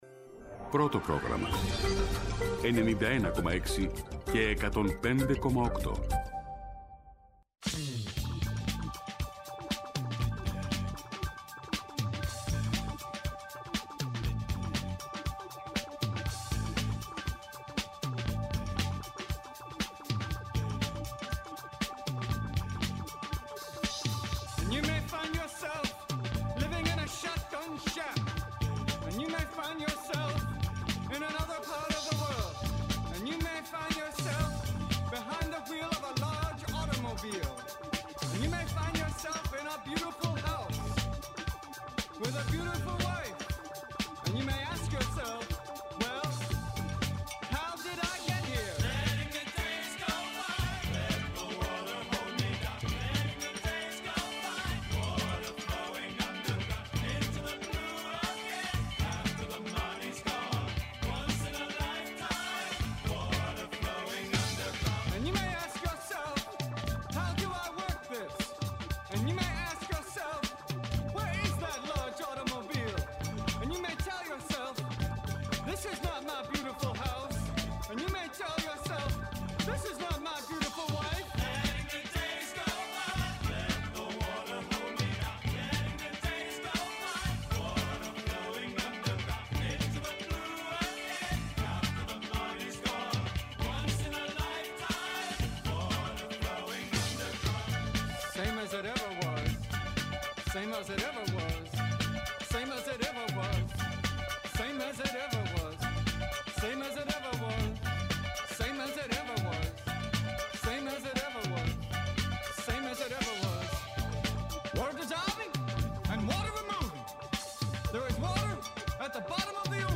Πόση επικαιρότητα μπορεί να χωρέσει σε μια ώρα; Πόσα τραγούδια μπορούν να σε κάνουν να ταξιδέψεις Πόσες αναμνήσεις μπορείς να ανασύρεις ;